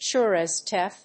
アクセント(as) súre as déath